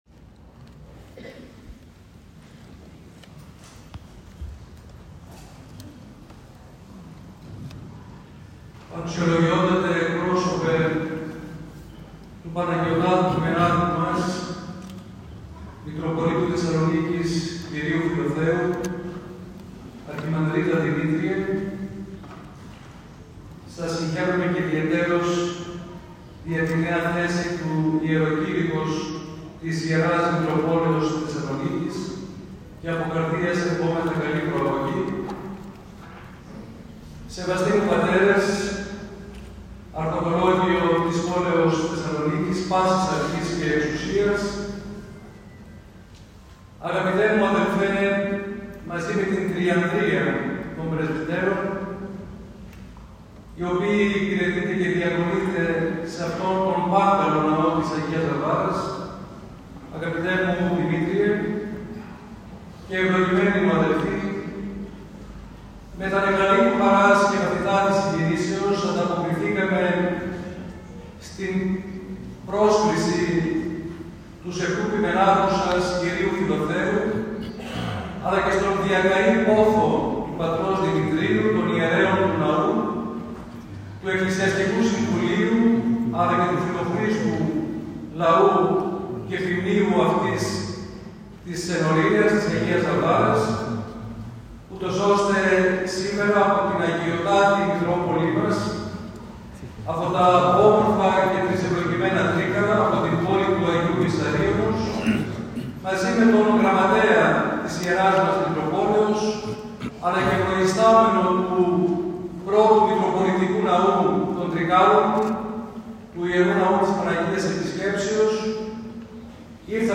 Κλήρος και λαός υποδέχτηκαν με τις πρέπουσες τιμές την Τίμια Κάρα της Αγίας Μεγαλομάρτυρος Βαρβάρας. Στη συμβολή των οδών Γρηγορίου Λαμπράκη και Διαγόρα, πλήθος κόσμου συγκεντρώθηκε για να συμμετάσχει στην τελετή υποδοχής και να τιμήσει την Αγία.